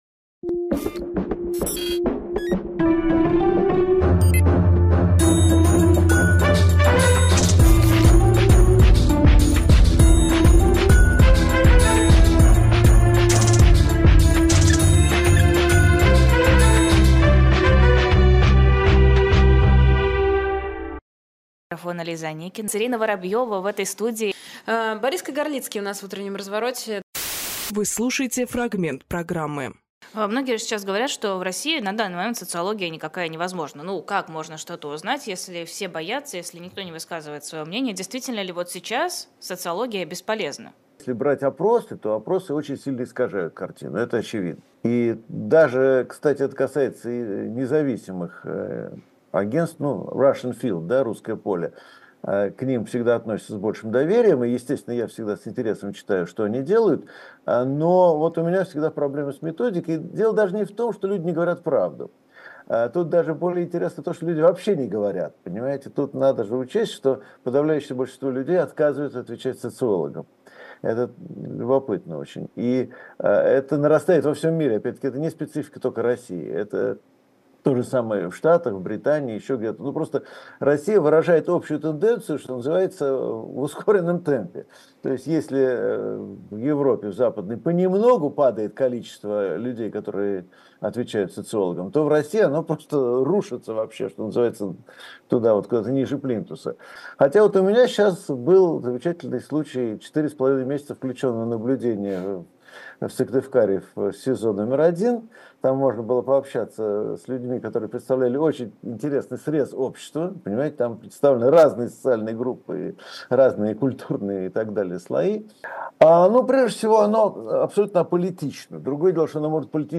Борис Кагарлицкийсоциолог, кандидат исторических наук
Фрагмент эфира от 21.01.24